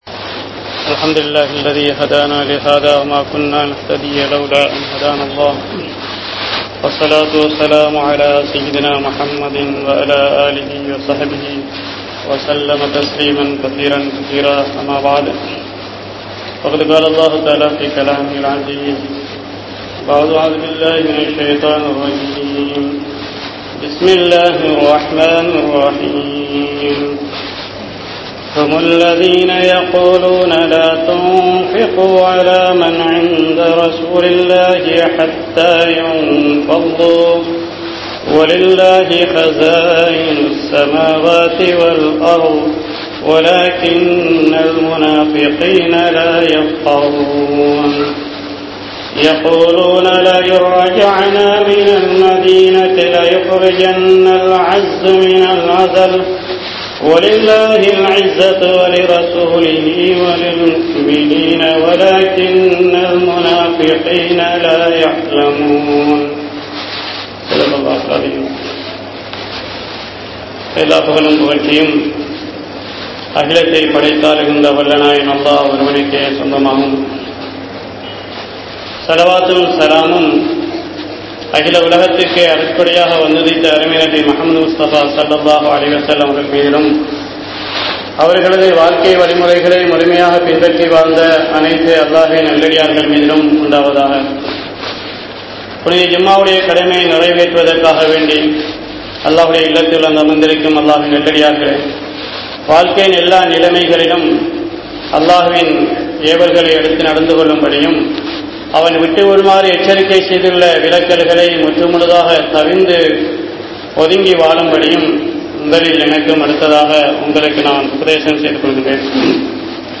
Vaanam Boomi Thaangaatha Paavangal (வானம் பூமி தாங்காத பாவங்கள்) | Audio Bayans | All Ceylon Muslim Youth Community | Addalaichenai
Town Jumua Masjidh